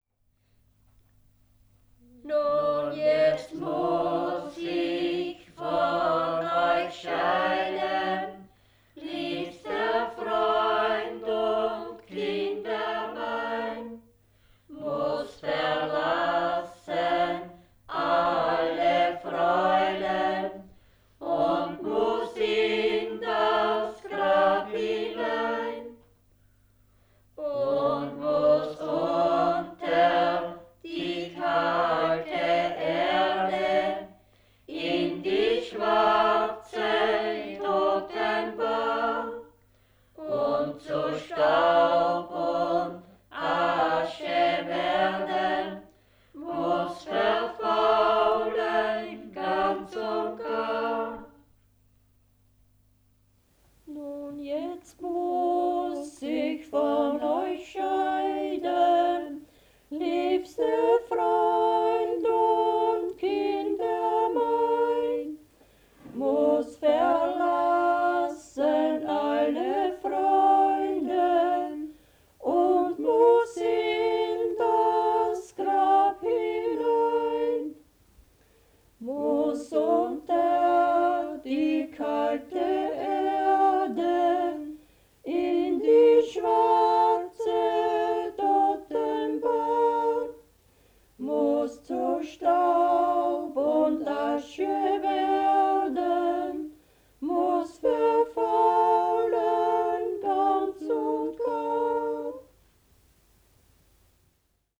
Die „Leichhüatlieder“, welche zwei Nächte lang im Hause eines Verstorbenen vor dem aufgebahrten Toten gesungen wurden. 192 Lieder mit Text- und Melodievarianten, Melodienincipits, 3 CDs mit historischen Tonaufzeichnungen, Wörterbuch des lokalen Dialekts.
Traditional music of the Wechsel, styrian-lower austrian border region, 100 kms south of Vienna. Volume 1 „The religious song“ sung during the farmer’s traditional two-night corpse-watch at the bier in the house of the deceased. 192 songs with text, music and incipits, 3 CDs with historical recordings, dictionary of local dialect.
Church music
Folk & traditional music